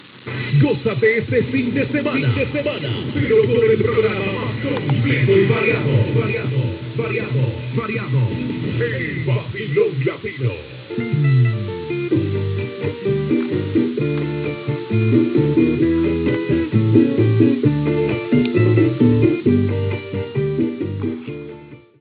Identificicació del programa i tema musical
FM